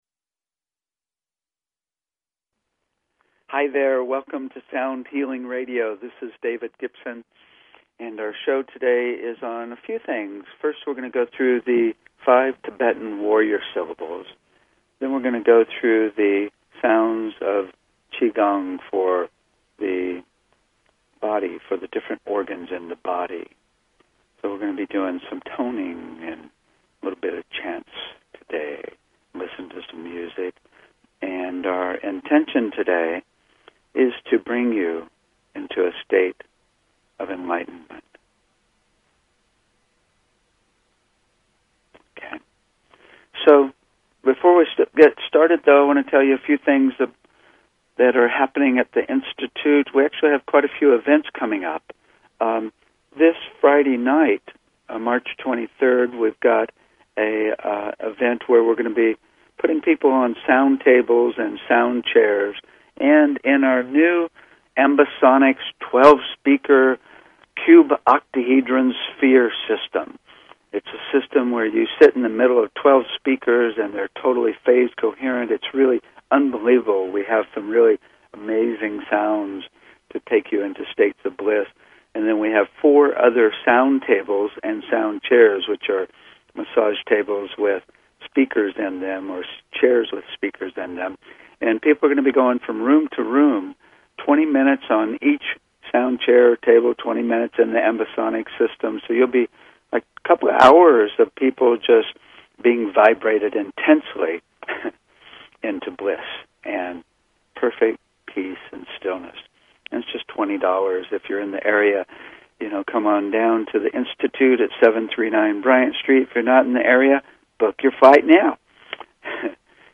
Talk Show Episode, Audio Podcast, Sound_Healing and Courtesy of BBS Radio on , show guests , about , categorized as
THE FIVE TIBETAN WARRIOR SYLLABLES and SOUNDS OF QiGONG Discussing and toning these ancient sounds.